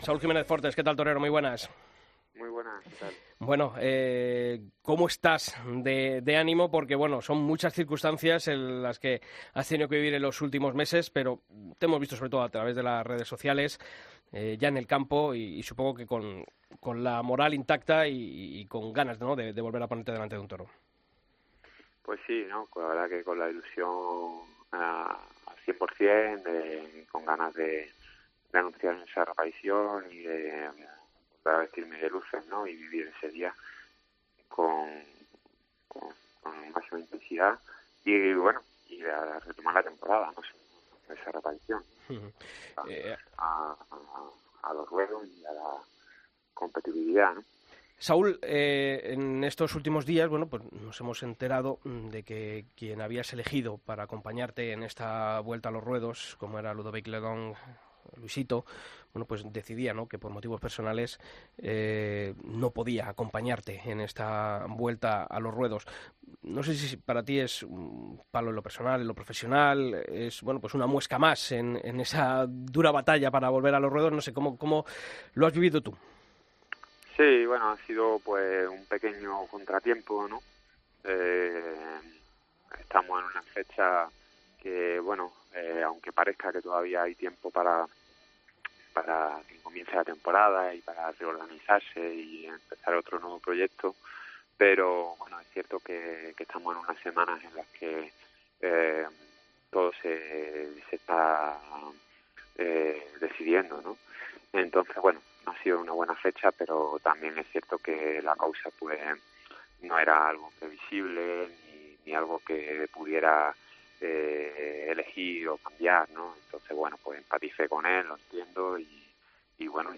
AUDIO: Hablamos con Saúl Jiménez Fortes, que reaparecerá este año tras sufrir una infección de huesos la pasada temporada